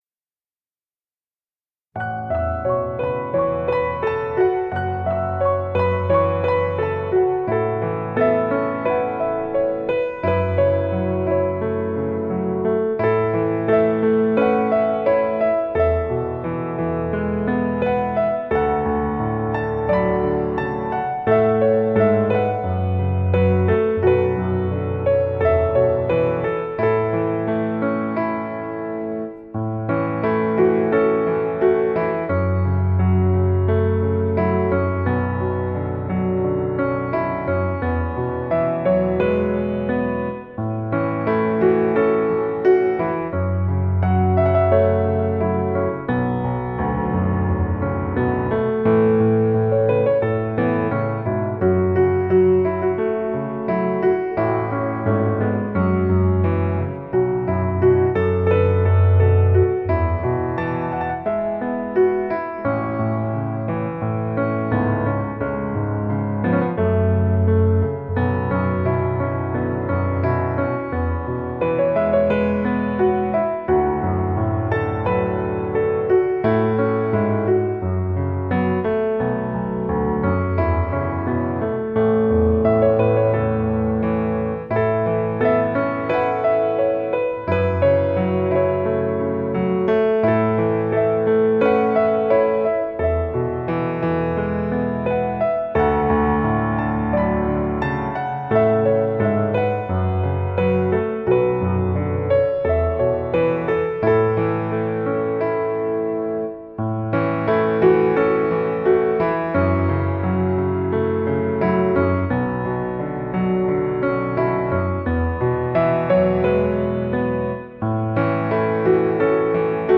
铿锵玲珑的斯坦威钢琴 将这一段段记忆中的旋律 丰润而细腻地浓情演绎
指尖上的芭蕾 黑与白的悸动 似灵魂在琴键上跳舞